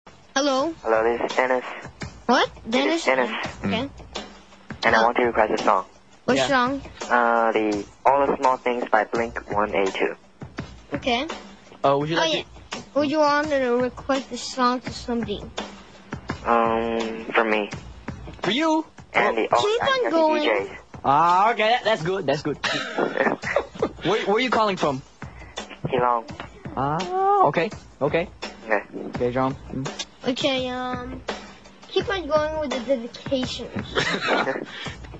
As is typical with New Englishes there is much phonetic interference from background languages. In the case of Chinese English, vowel length is not distinctive and consonant clusters are greatly simplified. In addition the intonation used is quite different from native forms of English, something which impairs comprehensibility.
Taiwan_English.wav